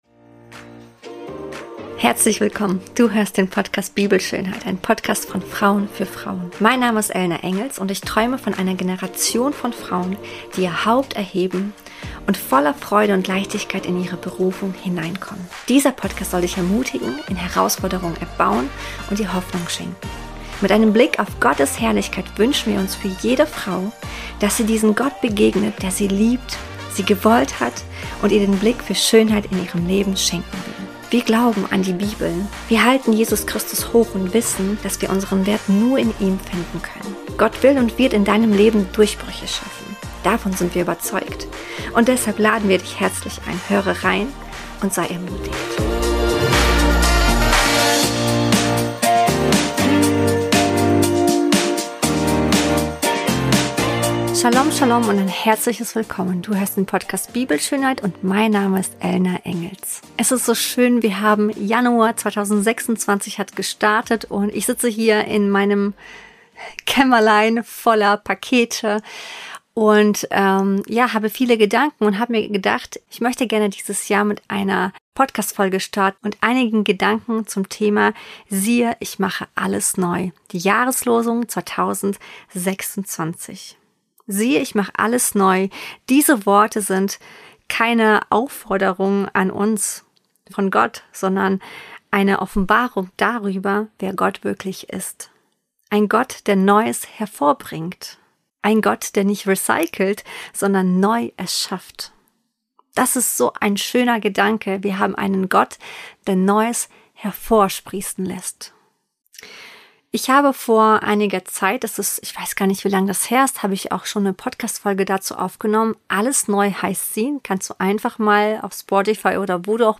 In dieser geführten Meditation lade ich dich ein, zur Ruhe zu kommen und deinem Herzen Raum zu geben.